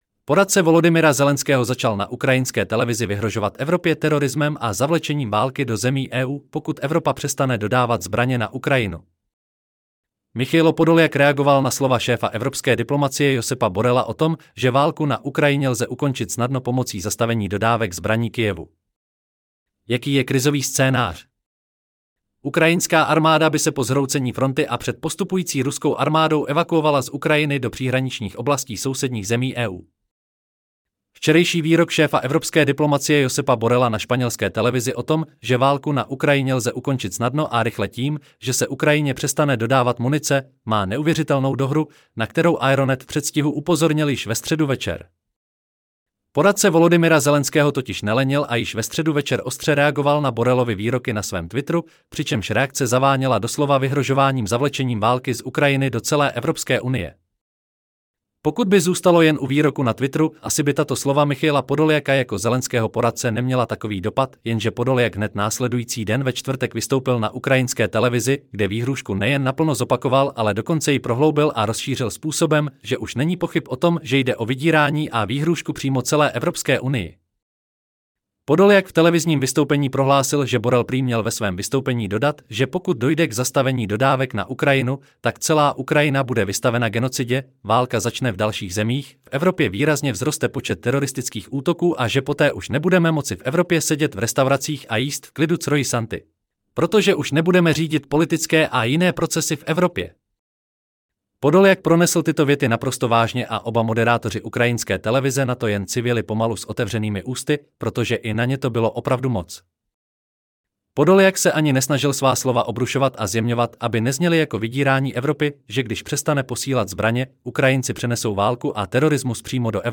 Celý článek si můžete poslechnout v audio verzi ZDE: PORADCE VOLODYMYRA ZELENSKÉHO ZAČAL NA UKRAJINSKÉ TELEVIZI VYHROŽOVAT EVROPĚ TERORISMEM A...